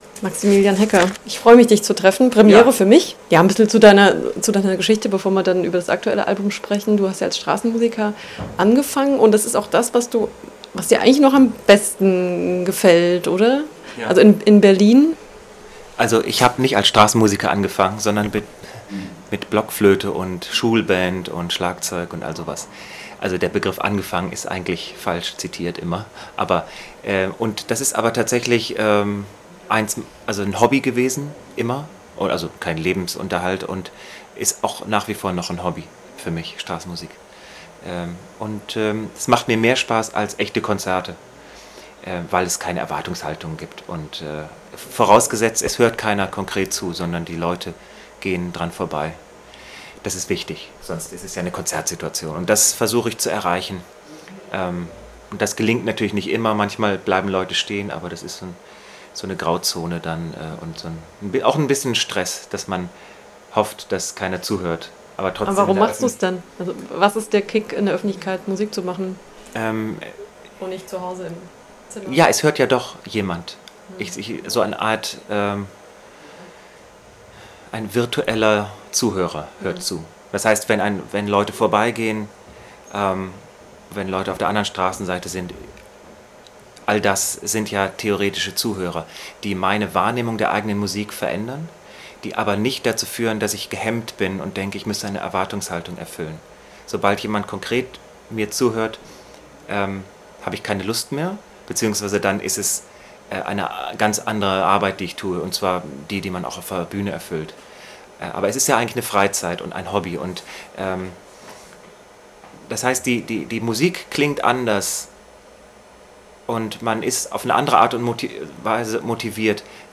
Maximilian Hecker im Interview